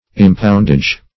Search Result for " impoundage" : The Collaborative International Dictionary of English v.0.48: Impoundage \Im*pound"age\ ([i^]m*pound"[asl]j), n. 1.